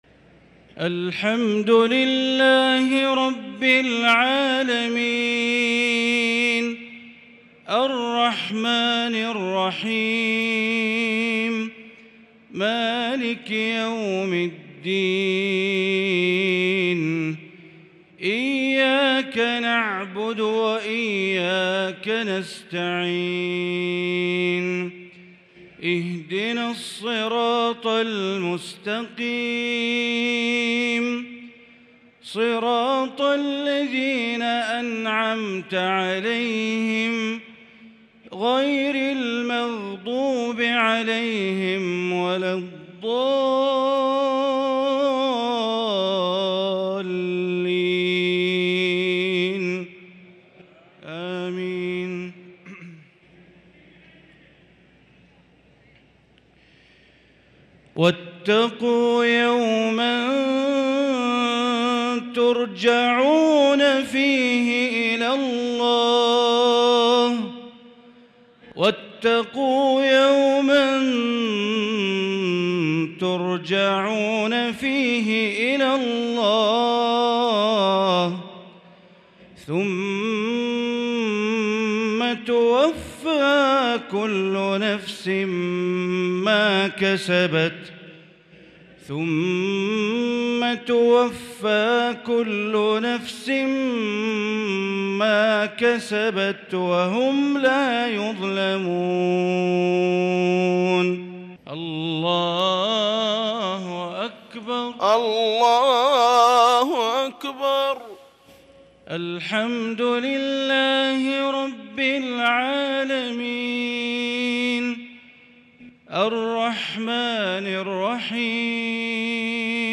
صلاة المغرب للقارئ بندر بليلة 12 ذو الحجة 1443 هـ
تِلَاوَات الْحَرَمَيْن .